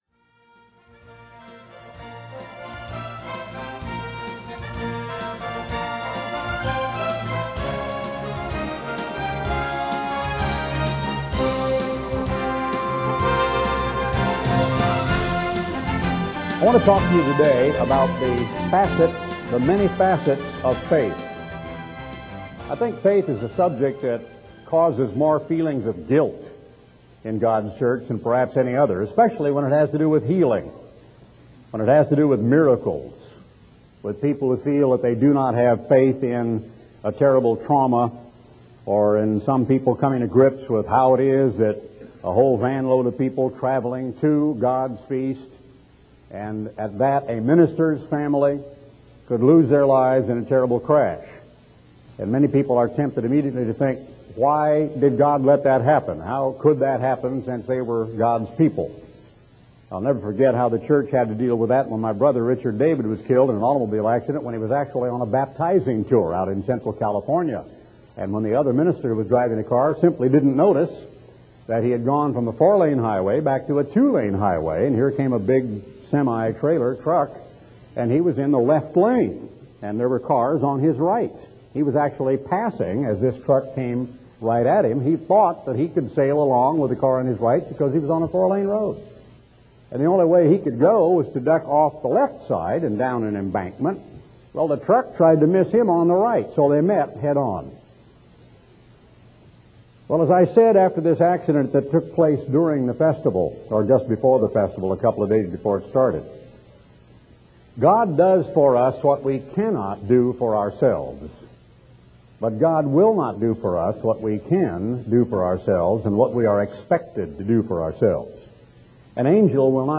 The Many Facets of Faith - Sermons ~ Garner Ted Armstrong Sermons (audio) Podcast
Message from Garner Ted Armstrong on October 24, 1992